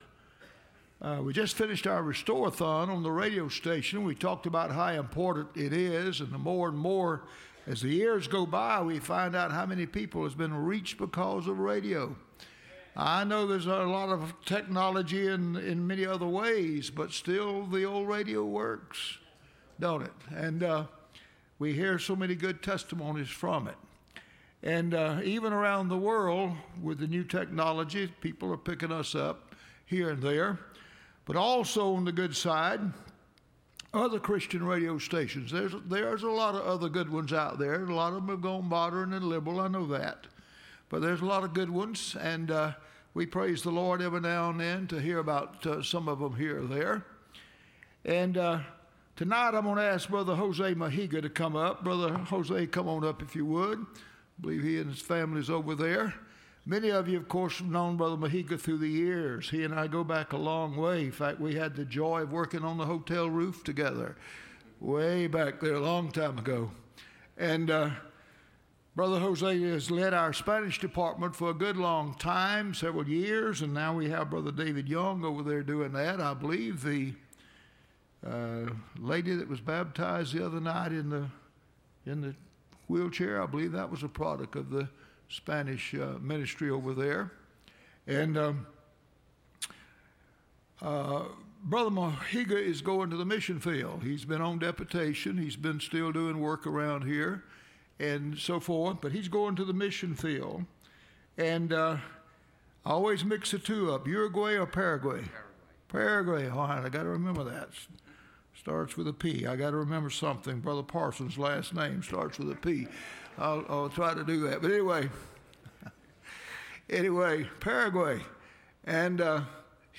Missions Presentaion – Landmark Baptist Church
Service Type: Wednesday